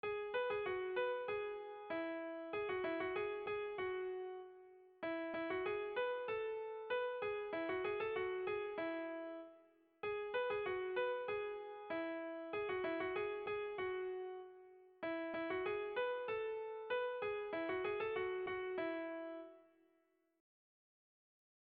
Erlijiozkoa
Zortziko txikia (hg) / Lau puntuko txikia (ip)
ABAB